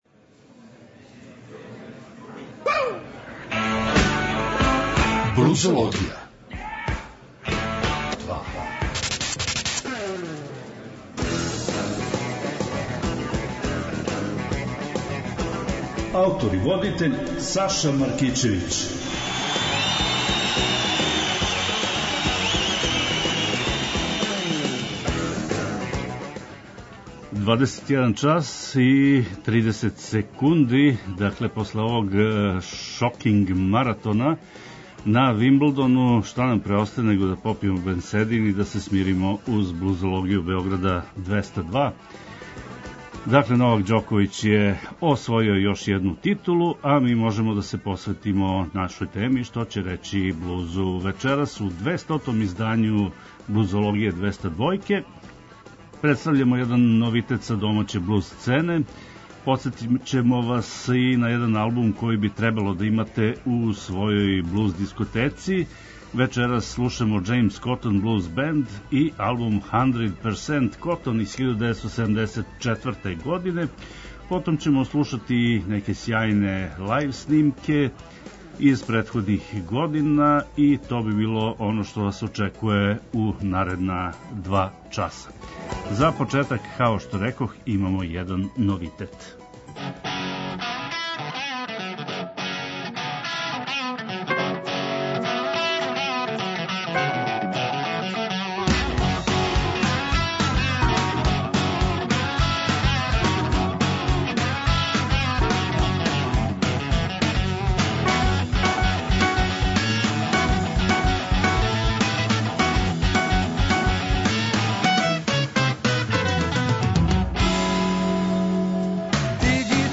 Поштујући захтеве многих слушалаца, Београд 202 је од 10. јануара 2015. покренуо нову музичку емисију под називом „Блузологија“.
Нудимо вам избор нових музичких издања из овог жанра, али не заборављамо ни пионире који су својим радом допринели развоју блуза и инспирисали младе музичаре широм света да се заинтересују и определе за професионалну каријеру у овој области.